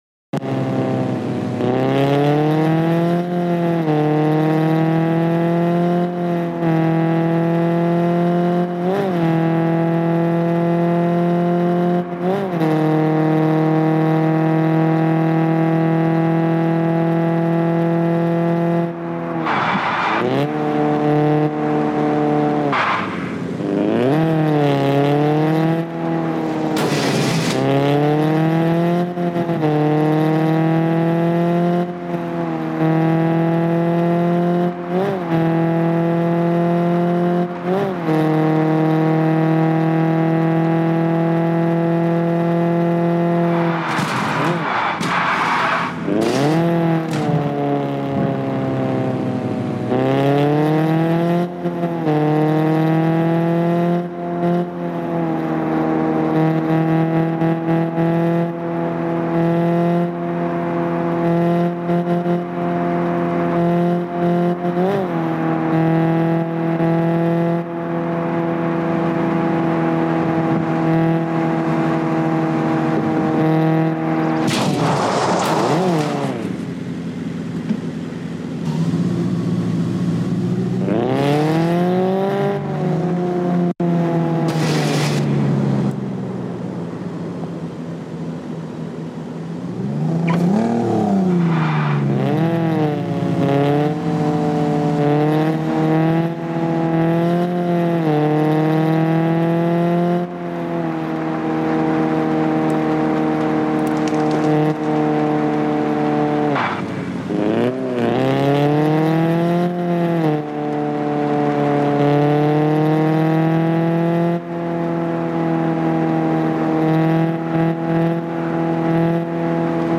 Welcome to the best Car Parking Multiplayer Gameplay! 🚗🔥 In this video, you’ll enjoy realistic driving, smooth car controls, and a fun delivery mission inside the Car Parking game.